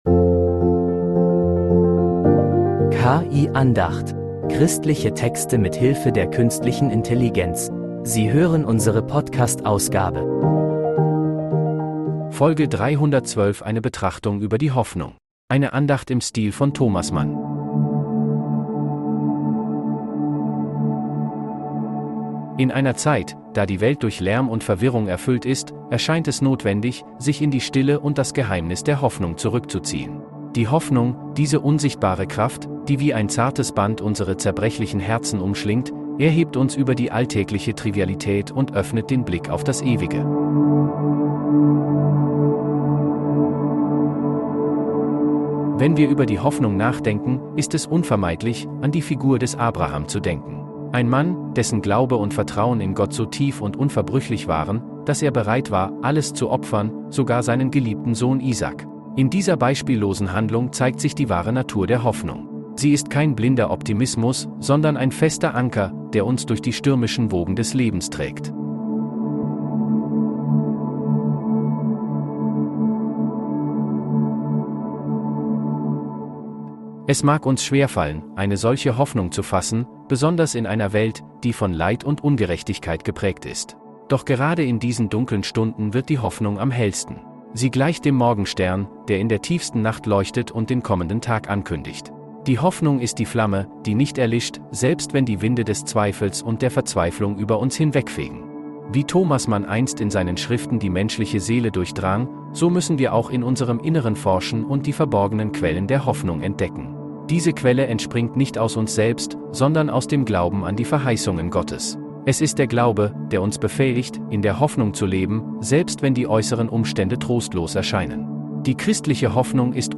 Eine Andacht im Stil von Thomas Mann